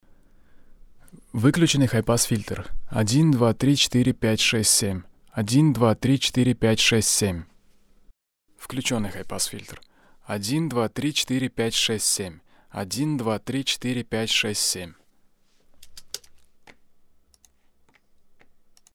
Добрый день, друзья, появился странный звук. Может, кто сталкивался. neumann U87ai пробовал 2 преампа - проблема не с преампом, а с микрофоном запсиываемый звук не искажается пример во вложении буду благодарен за помощь.